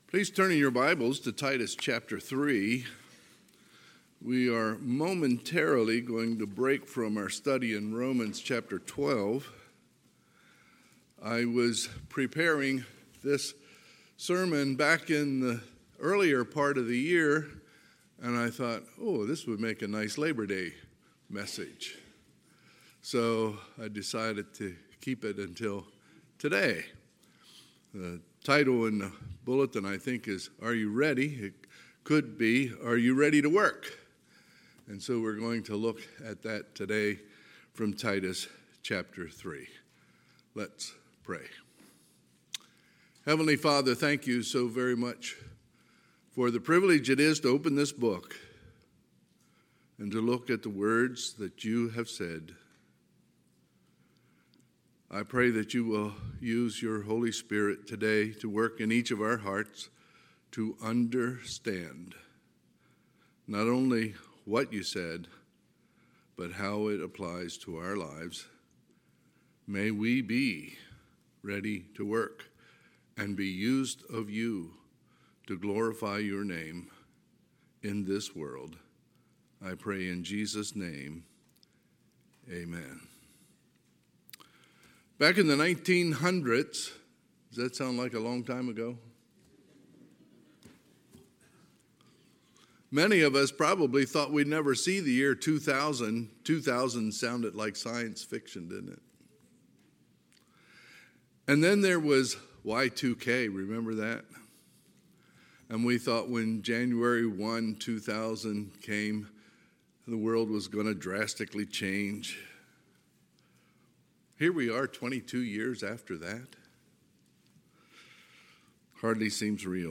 Sunday, September 4, 2022 – Sunday AM
Sermons